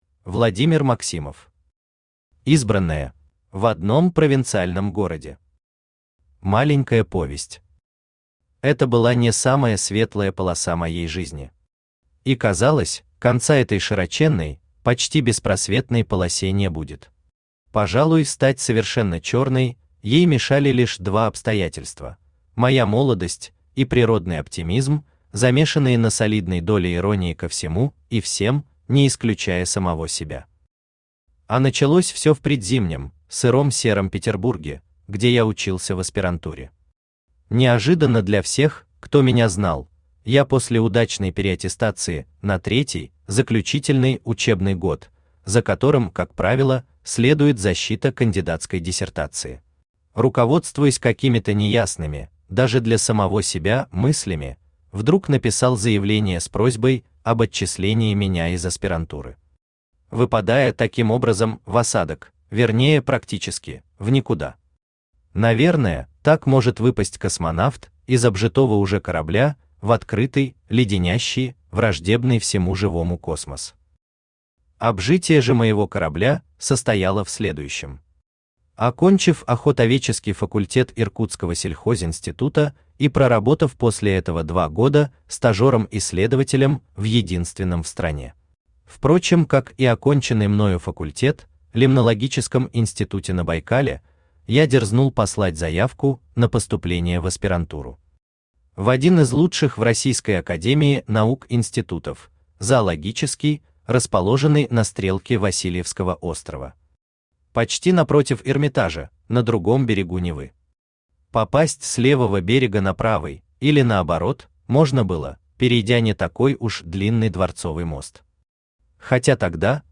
Aудиокнига Избранное Автор Владимир Павлович Максимов Читает аудиокнигу Авточтец ЛитРес.